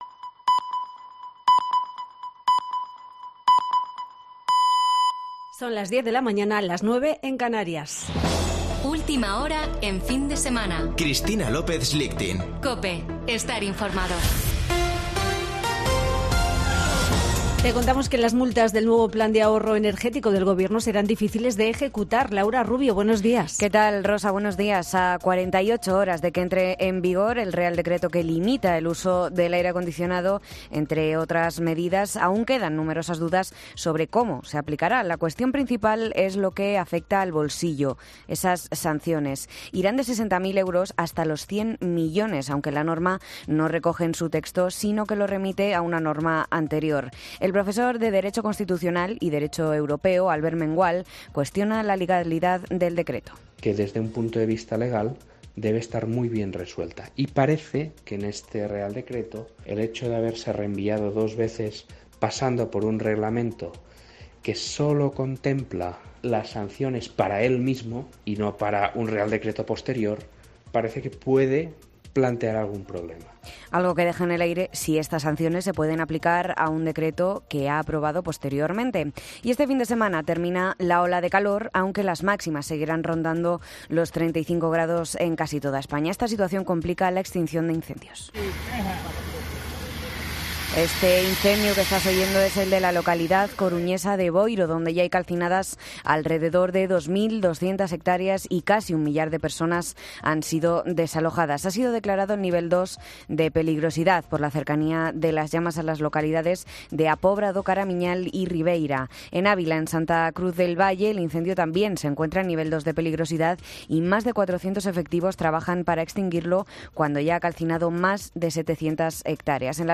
Boletín de noticias de COPE del 7 de agosto de 2022 a las 10.00 horas